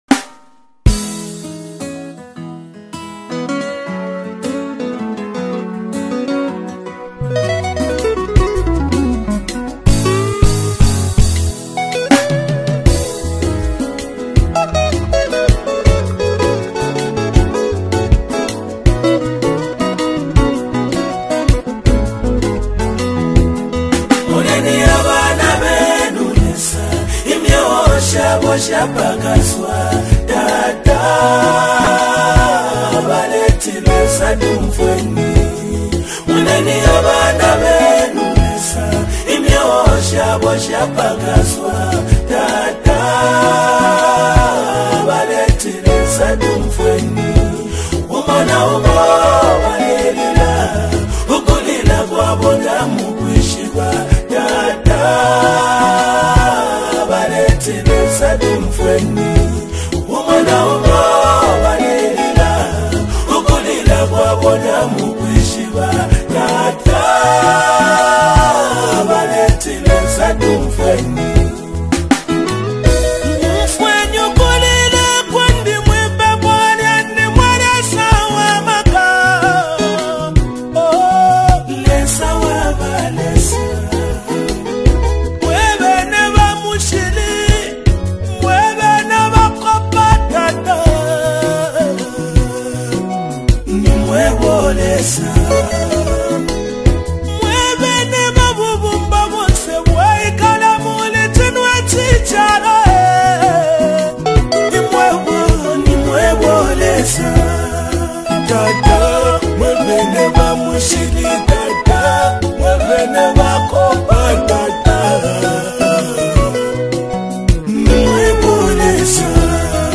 powerful melody